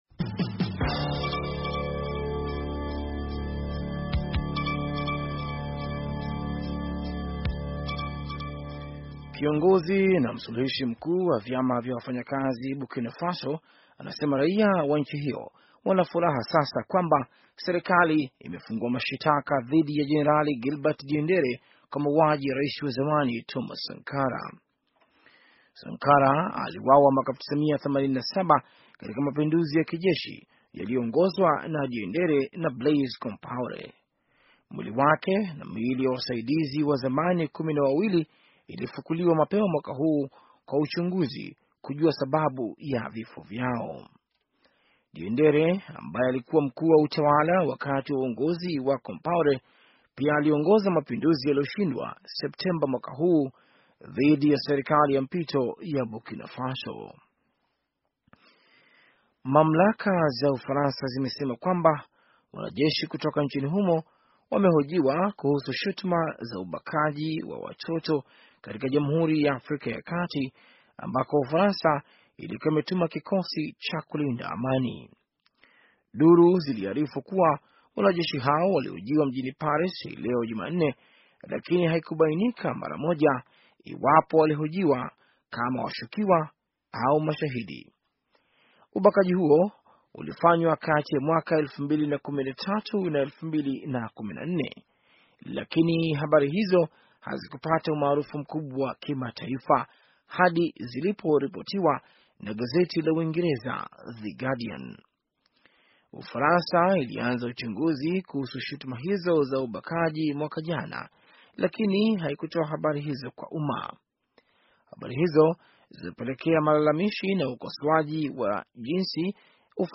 Taarifa ya habari - 5:28